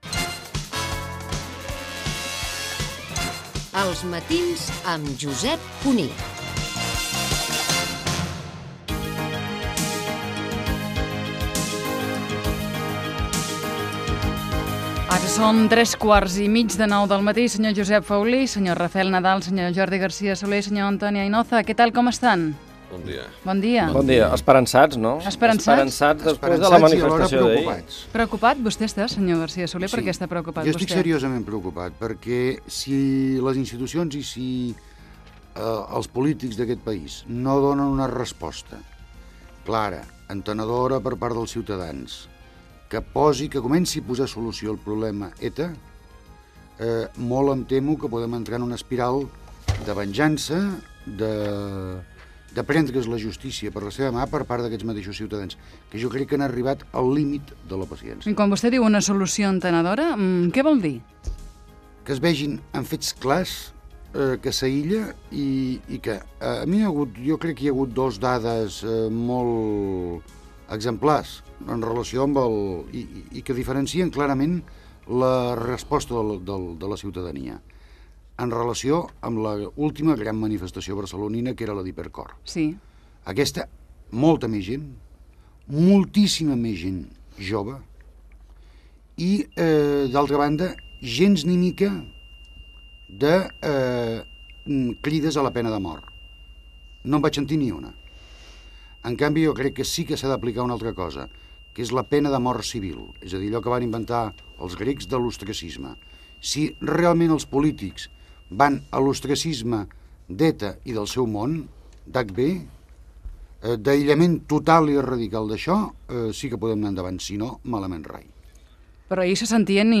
Indicatiu del programa, fragment de la tertúlia
Info-entreteniment
Fragment extret de l'arxiu sonor de COM Ràdio.